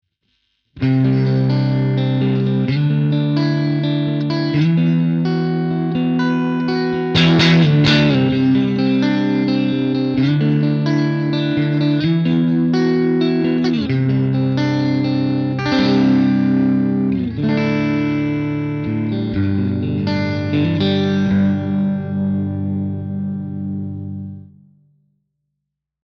All audio clips are recorded with a Marshall JCM900 head, set on an extremely clean tone. The cabinet used is a 2×12 openback with Celestion Creamback 75 speakers.
Clean sound, no effects added
Guitar: Fender
Mode: Super
Gain: 6/10